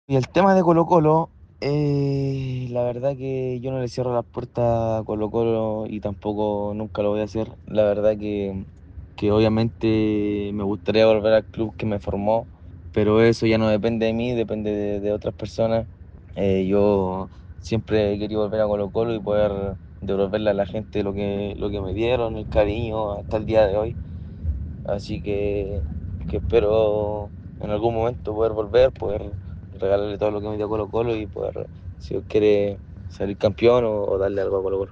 En conversación exclusiva con ADN Deportes, el atacante de 20 años también negó estar en rebeldía con su club en Turquía.